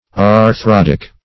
Meaning of arthrodic. arthrodic synonyms, pronunciation, spelling and more from Free Dictionary.
Search Result for " arthrodic" : The Collaborative International Dictionary of English v.0.48: Arthrodial \Ar*thro"di*al\, Arthrodic \Ar*throd"ic\, a. Of or pertaining to arthrodia.